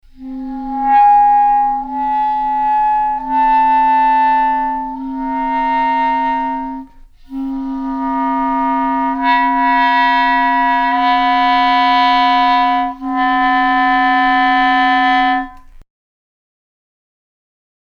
This seems to be supported by the following example, which shows a sequence of multiphonics with a lowest pitch that changes very little from one to the next (Example #2).
The upper pitches, however, gradually progress upwards.
02 145-multiphonic with same lh fing.mp3